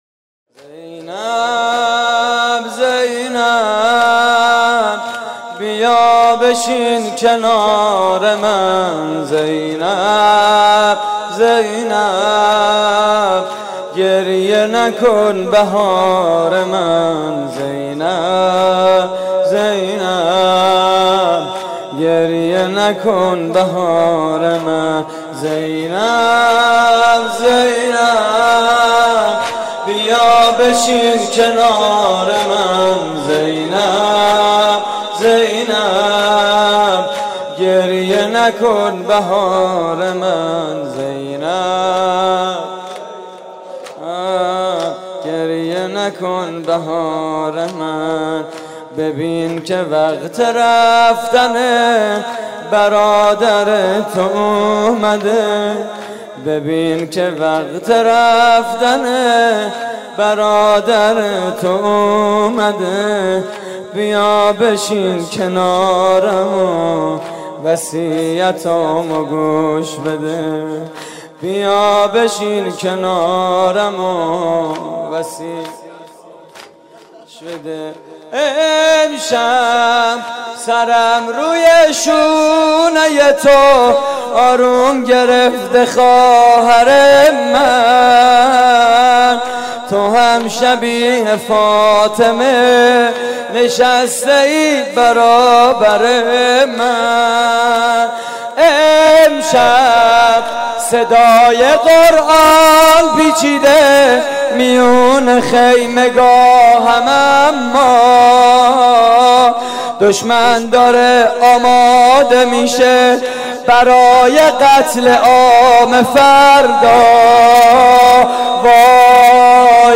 صوت مراسم: